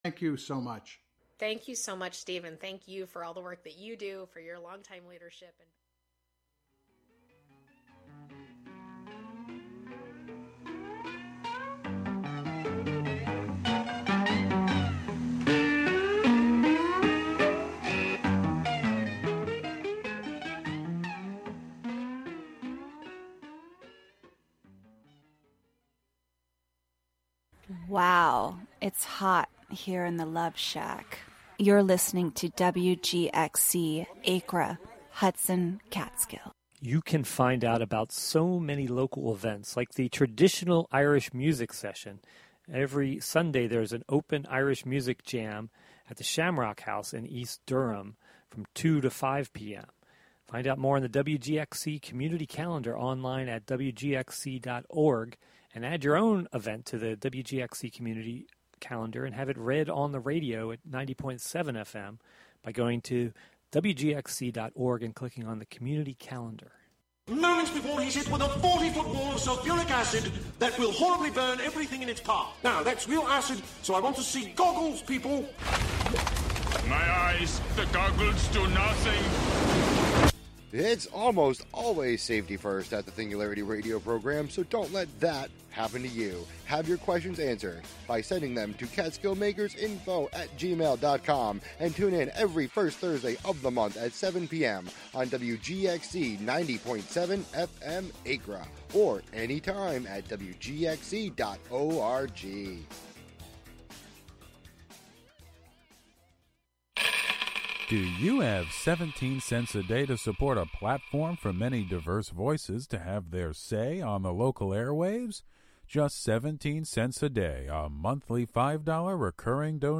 entertainment news and reviews
and the "Forgotten Decades" music mix of lesser-played and/or misremembered songs from the '50s through the '90s.